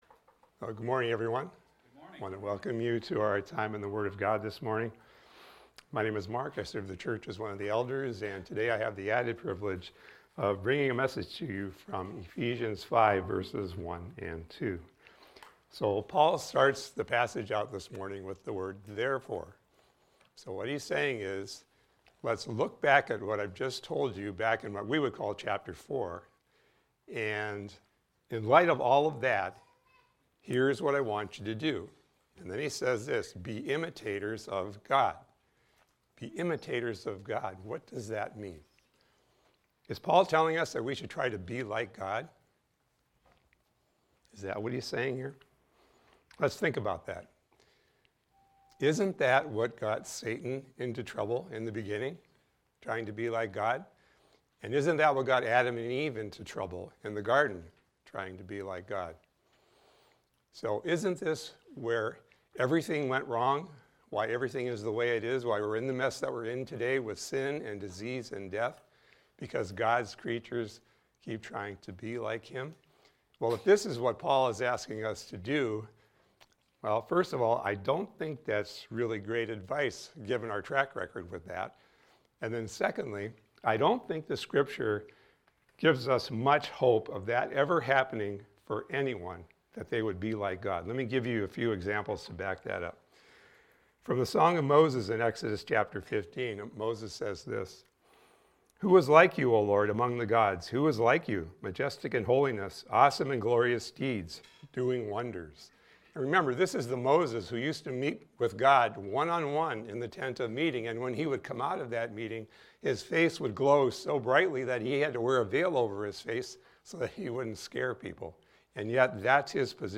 This is a recording of a sermon titled, "Walking In Love."